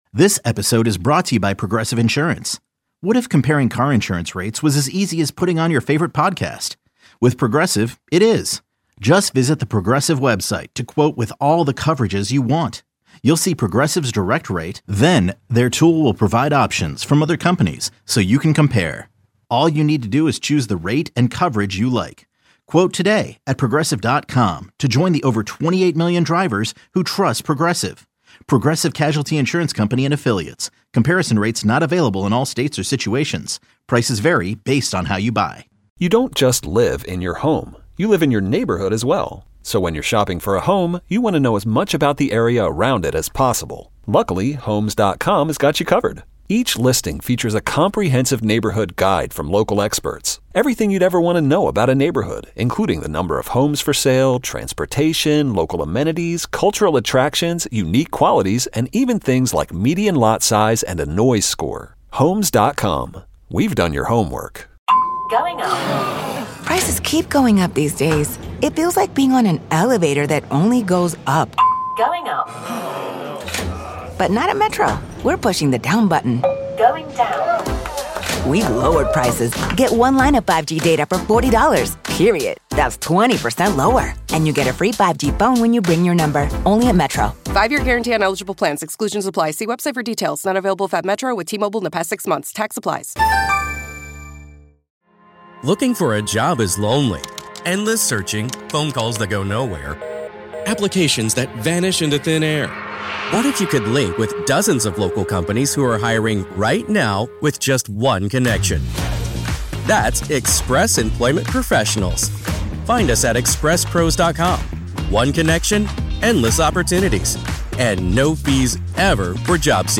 answers your calls and advises on tuckpointing, plumbing, and chimney issues.